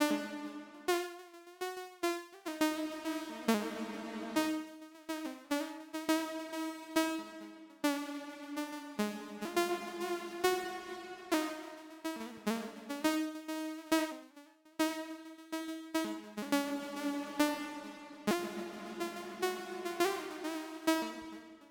Dit instrument is een synth, waarvan de amplitude bepaald wordt door een envelope follower op een drumloop, en de frequentie door pitch tracking op een melodieuze sample.
De trigger gaan we gebruiken om telkens een parameter van een effect willekeurig in te stellen.